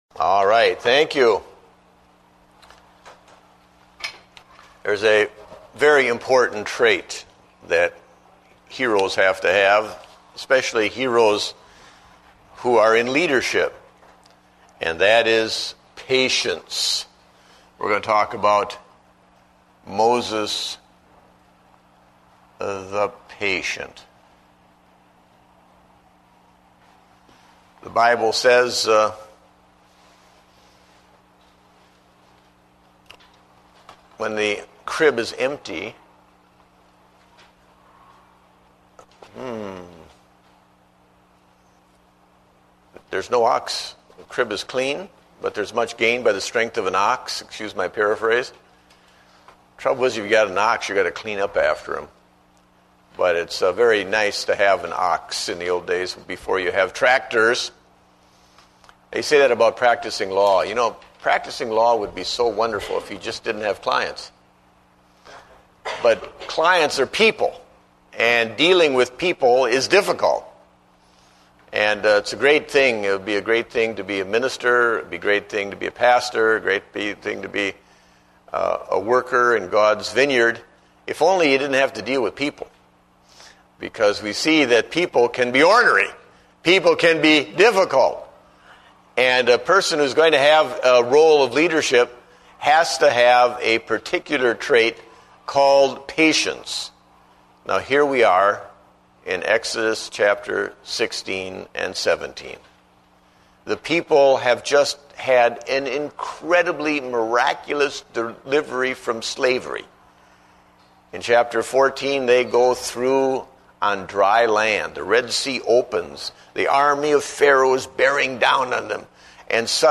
Date: June 28, 2009 (Adult Sunday School)